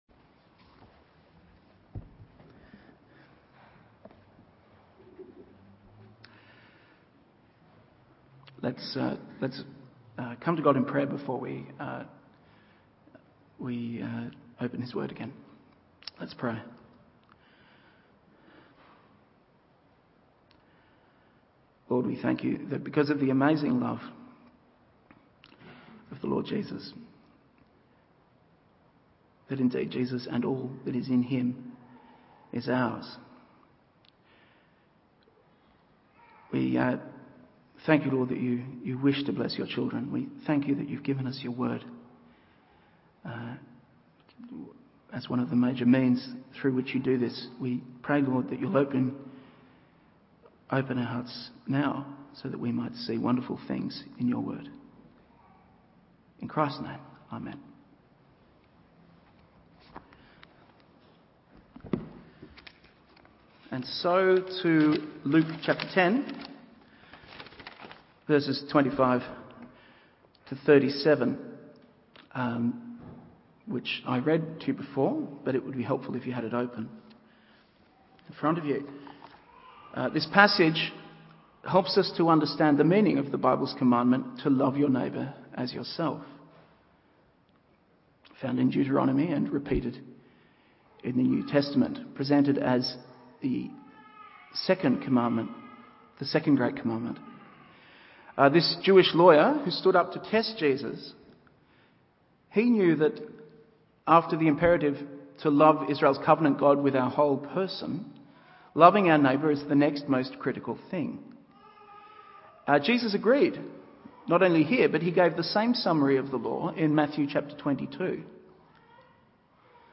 Evening Service Luke 10:25-37…